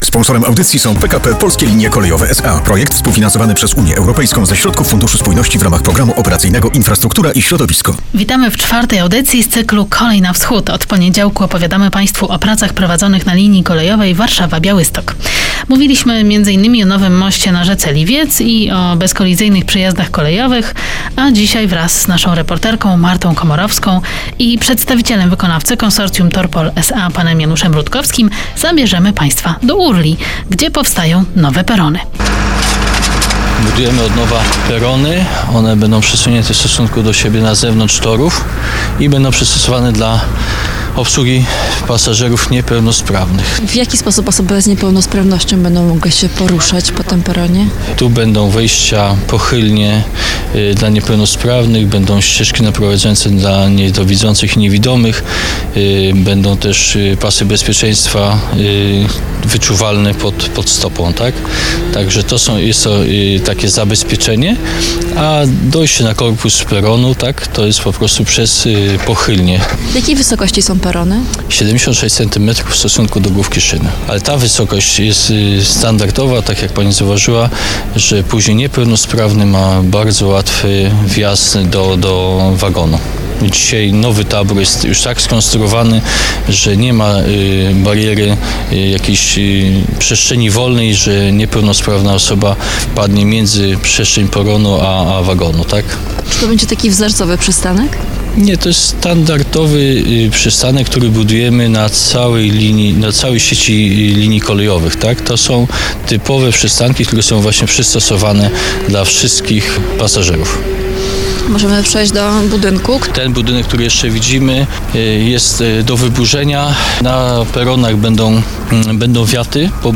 audycja radiowa